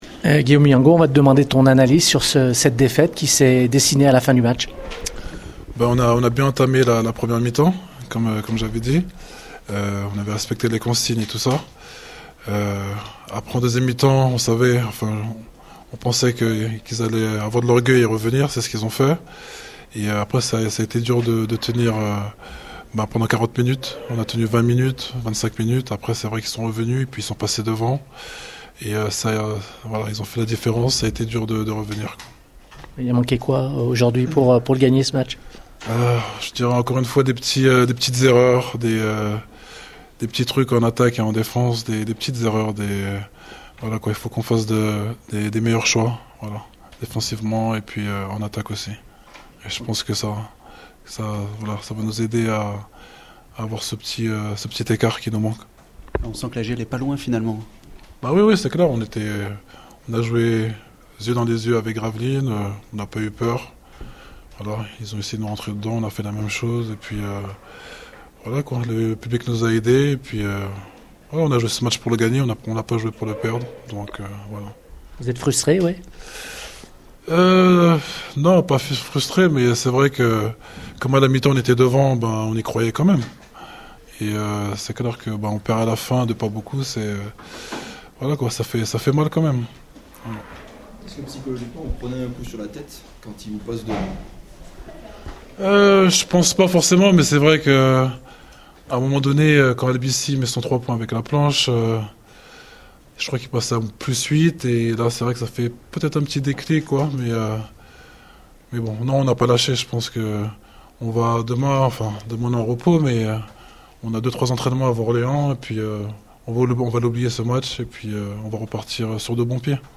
réactions d’après-match au micro Radio Scoop